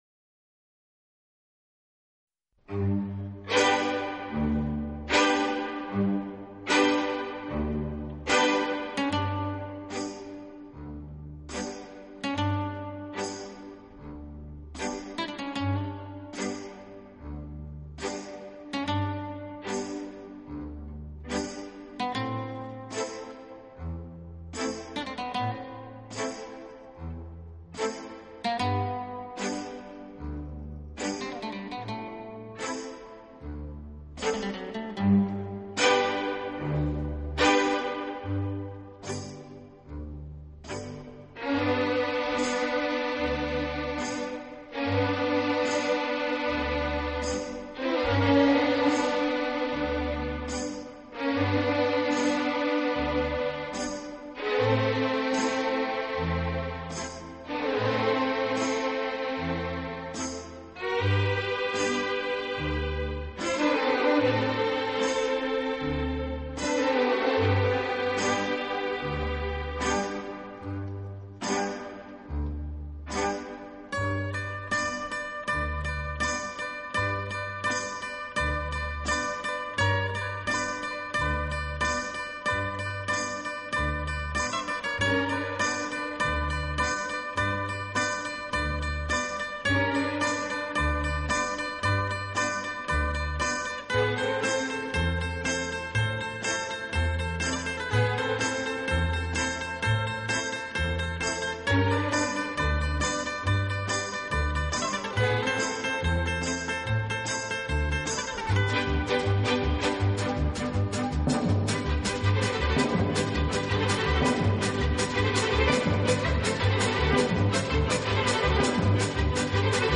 【轻音乐】
2、精心运用打击乐、尤其是一些特殊的打击乐器，小号、
3、乐队演奏以华丽著称，气势磅礴。